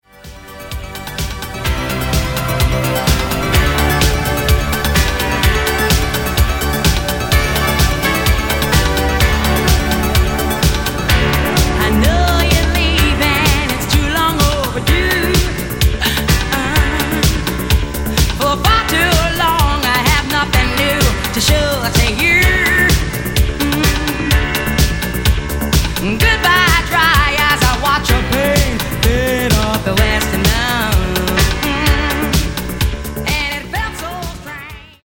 Genere:   Disco Elettronica